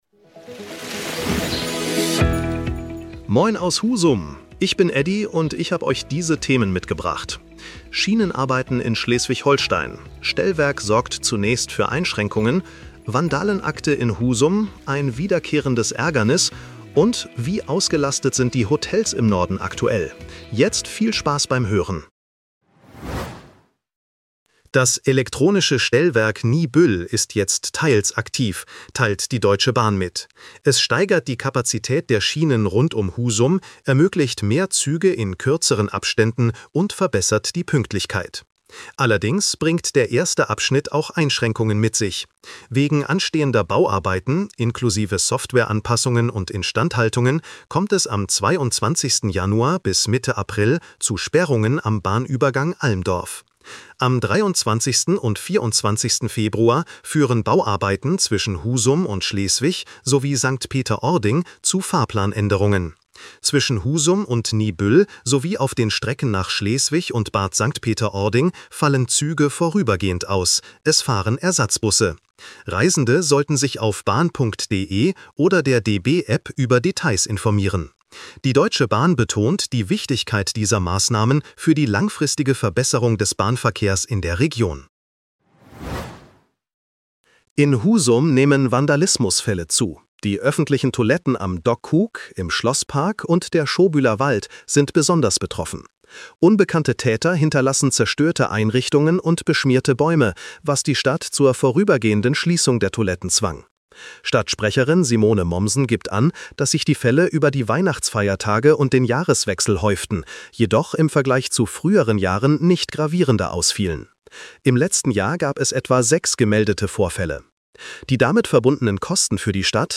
Deine täglichen Nachrichten
Nachrichten